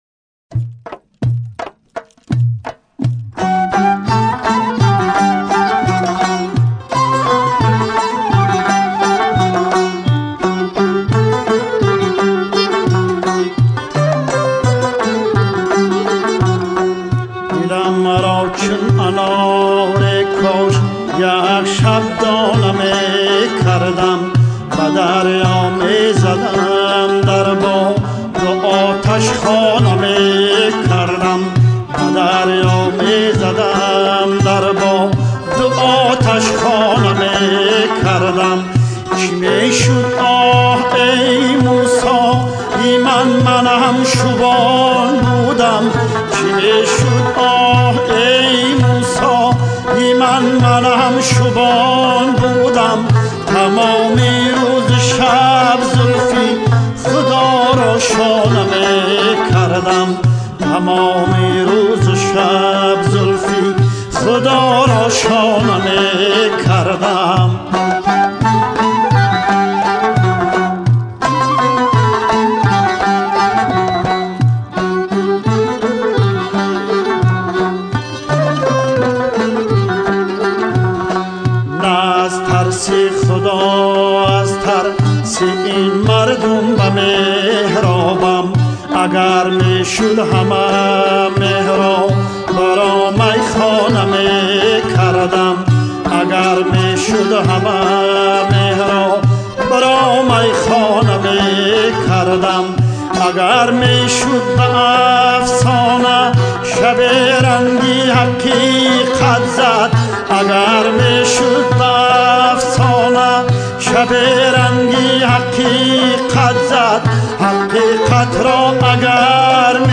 خواننده سرشناس موسیقی تاجیکستان